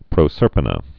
(prō-sûrpə-nə) also Pro·ser·pi·ne (prō-sûrpə-nē, prŏsər-pīn)